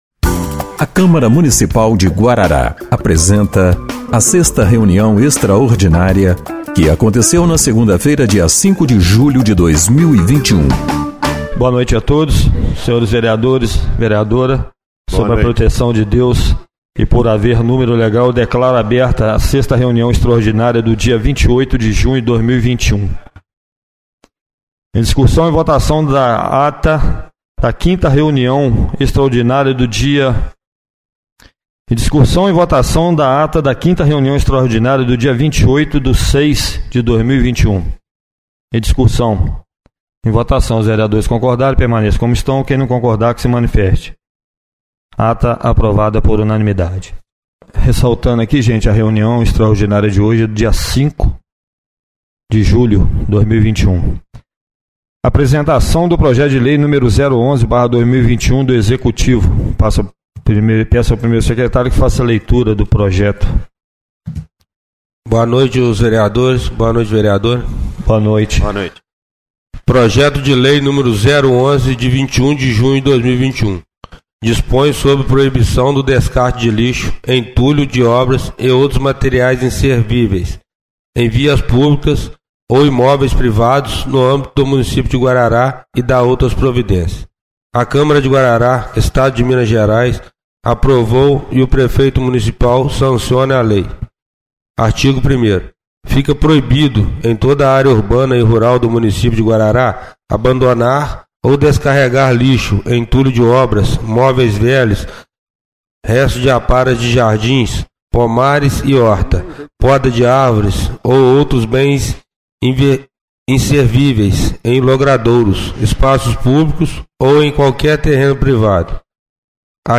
6ª Reunião Extraordinária de 05/07/2021